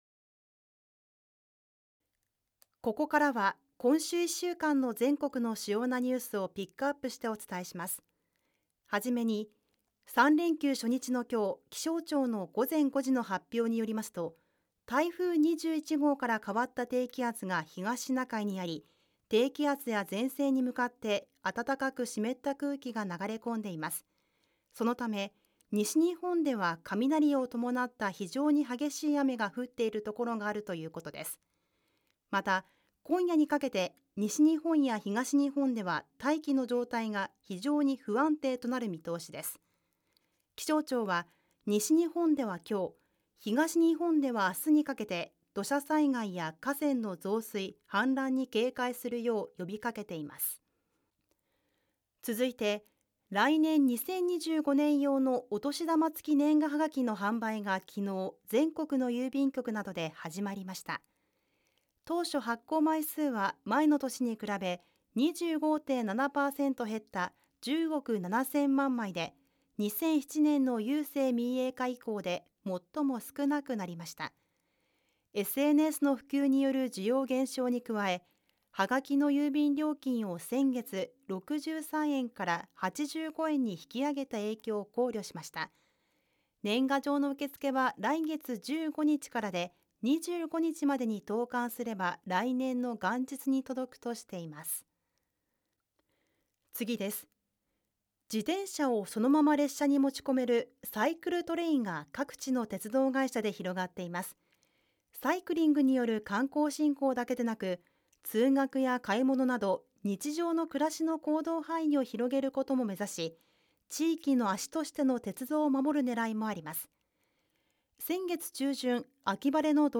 ボイスサンプル
ニュース読み
ニュース読み.mp3